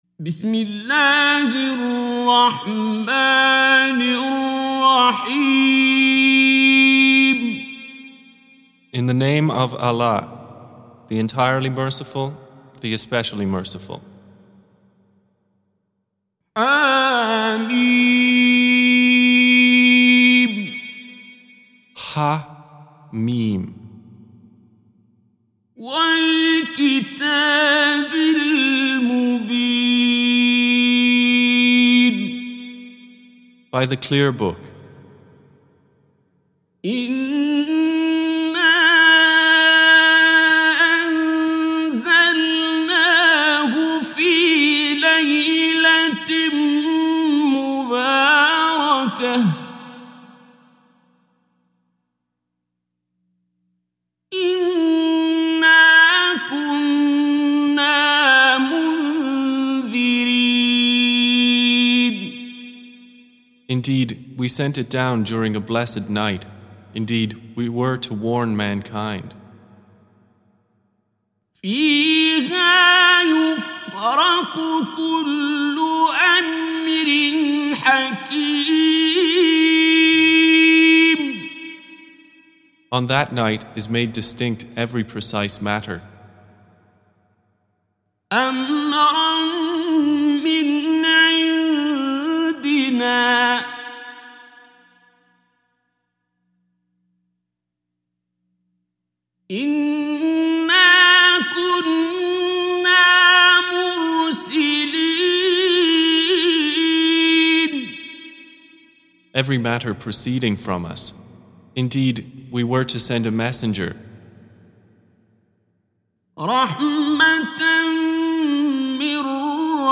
Surah Repeating تكرار السورة Download Surah حمّل السورة Reciting Mutarjamah Translation Audio for 44. Surah Ad-Dukh�n سورة الدّخان N.B *Surah Includes Al-Basmalah Reciters Sequents تتابع التلاوات Reciters Repeats تكرار التلاوات